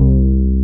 BAS.FRETC2-R.wav